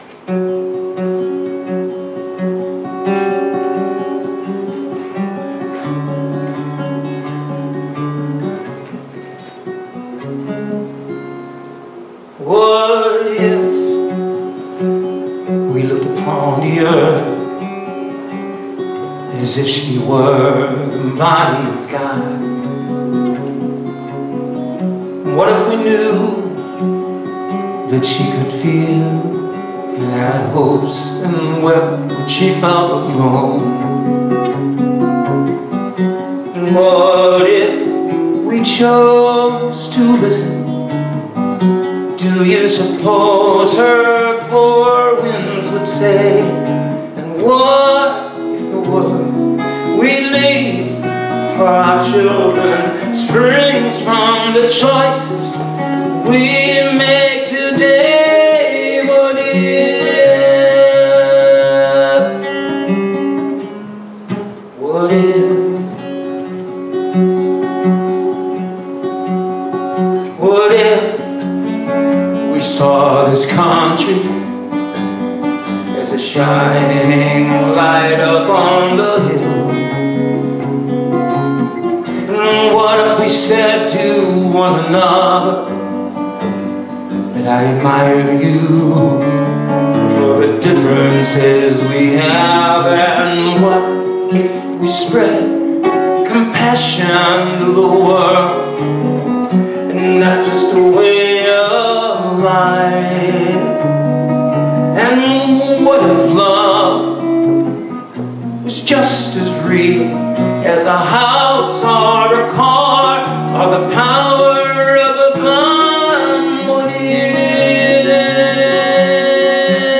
Series: Sermons 2026